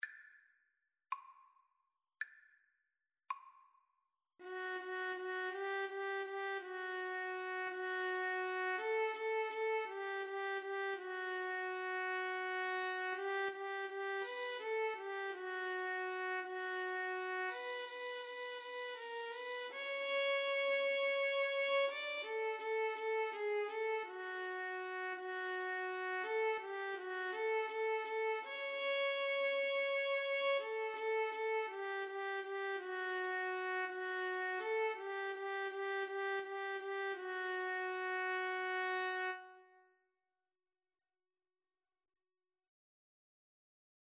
6/8 (View more 6/8 Music)
Classical (View more Classical Violin Duet Music)